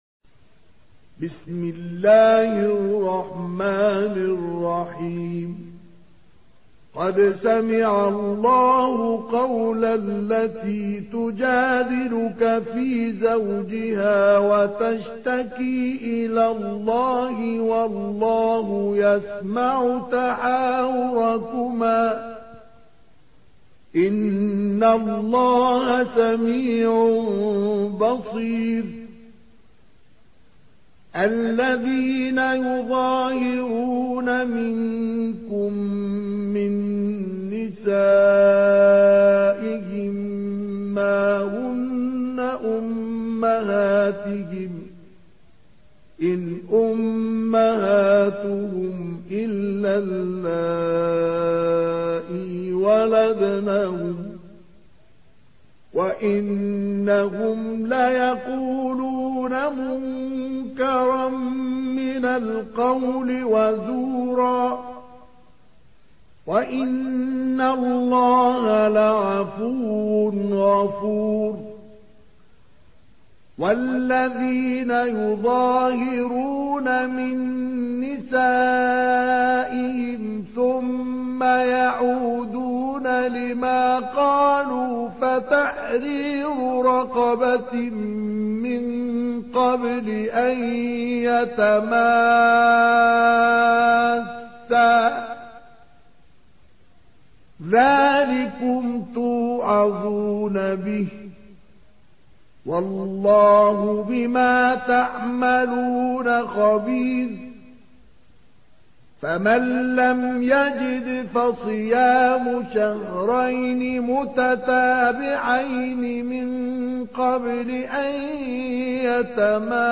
Surah Al-Mujadilah MP3 in the Voice of Mustafa Ismail in Hafs Narration
Murattal